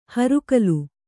♪ harukalu